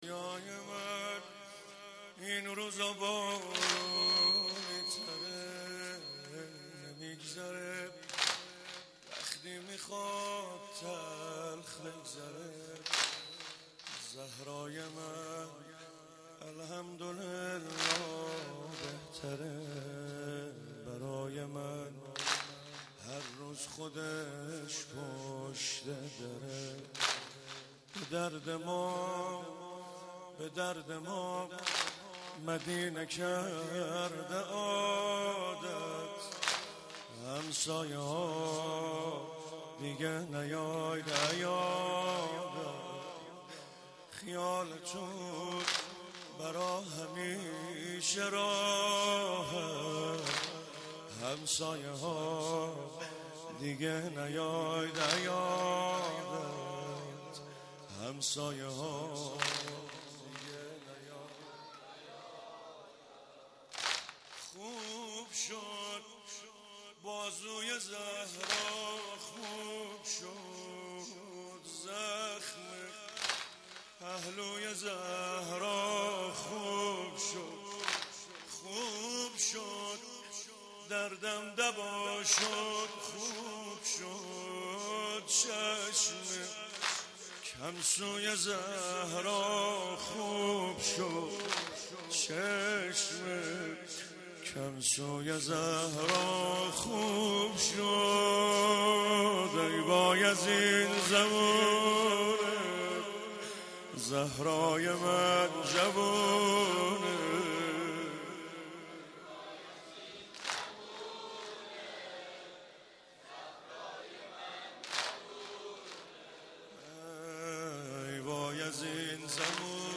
فاطمیه97 - 6 بهمن - میبد - واحد - دنیای من این روزا بارونی تره
فاطمیه 97